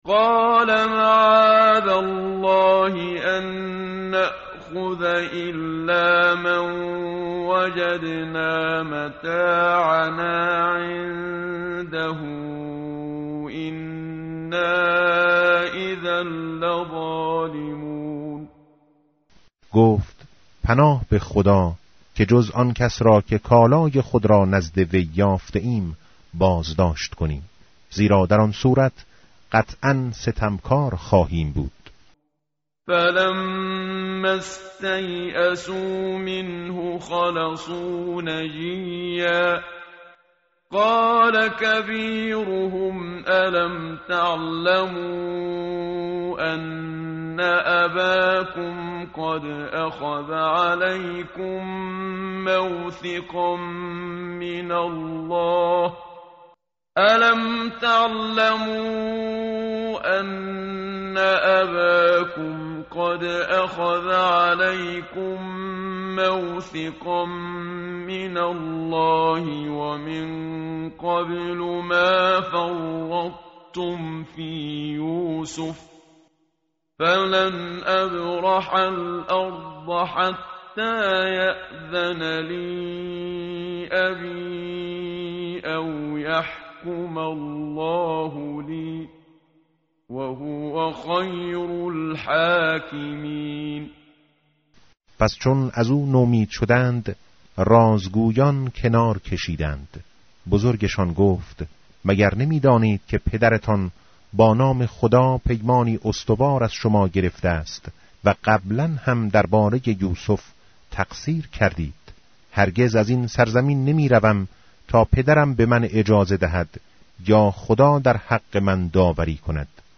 متن قرآن همراه باتلاوت قرآن و ترجمه
tartil_menshavi va tarjome_Page_245.mp3